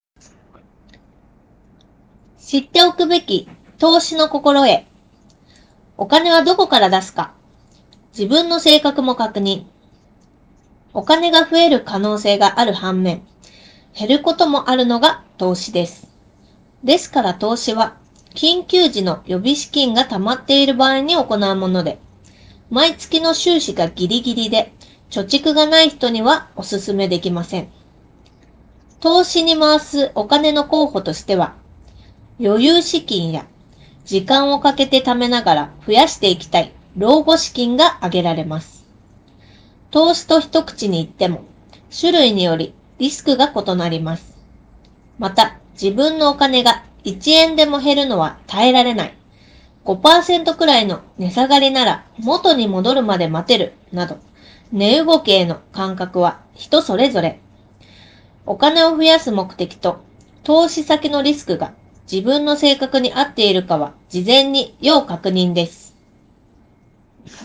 まず、スタッフが文字を読み上げる様子を、TALIX & DingTalk A1で録音すると同時に、文字起こし機能を試してみました。
▼TALIX & DingTalk A1のマイクで録音した音声
録音品質に関しては、全指向性マイク×5 + 骨伝導マイク×1を組み合わせた構成により、周囲の環境音を効果的に抑制し、話者の声をクリアに録音することができました。
talix-dingtalk-a1-review.wav